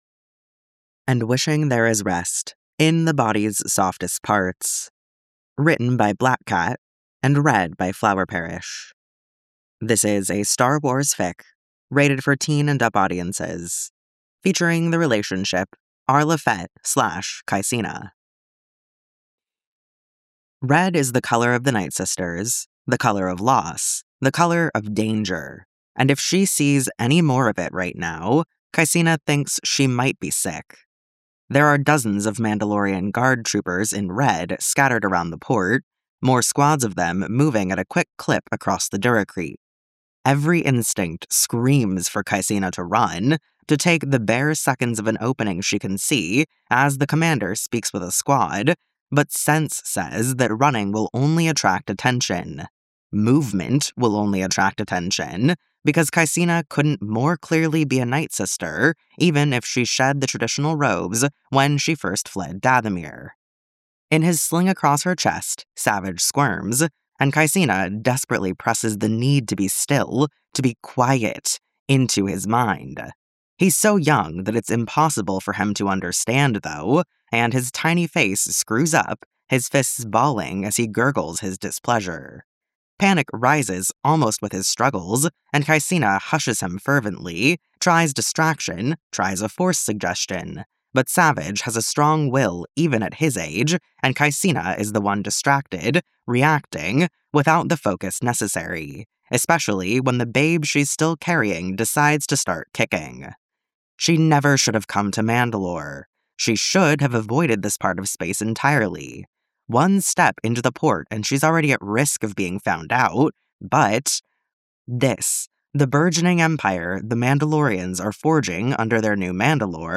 Full Music and Sound Effects download mp3: here (r-click or press, and 'save link') [62 MB, 01:09:55]